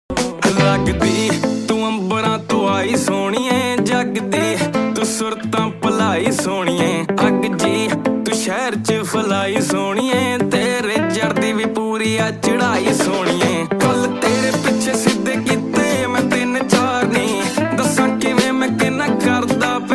Dj Remix Ringtone